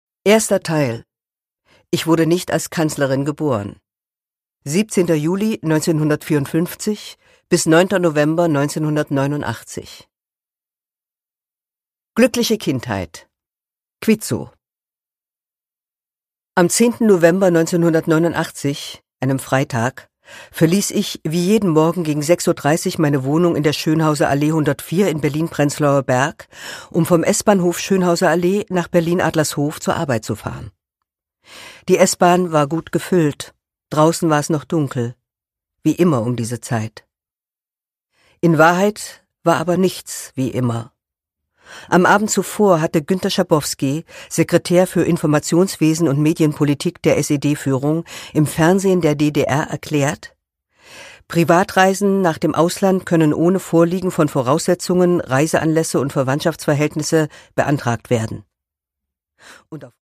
Produkttyp: Hörbuch-Download
Gelesen von: Corinna Harfouch, Angela Merkel